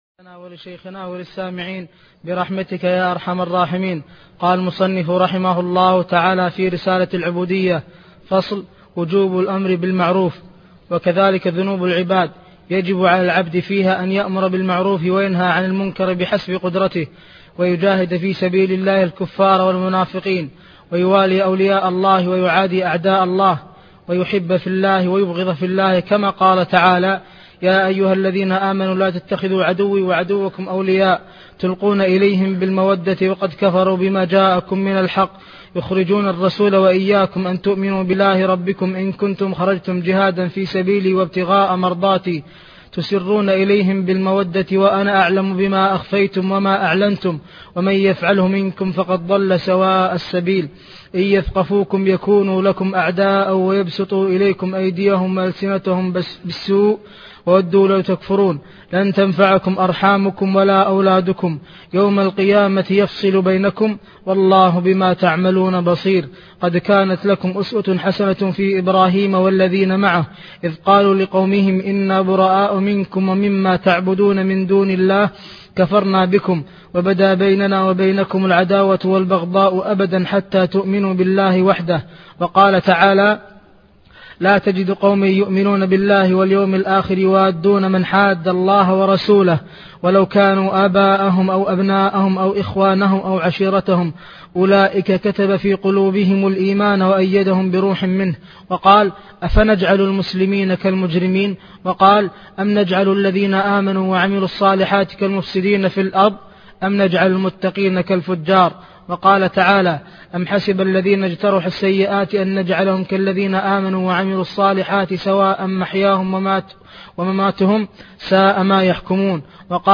تفاصيل المادة عنوان المادة الدرس (3) شرح رسالة العبودية تاريخ التحميل الخميس 9 فبراير 2023 مـ حجم المادة 27.98 ميجا بايت عدد الزيارات 202 زيارة عدد مرات الحفظ 102 مرة إستماع المادة حفظ المادة اضف تعليقك أرسل لصديق